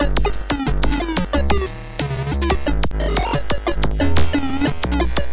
描述：电子循环（180bpm）
Tag: 回路 电子 180bpm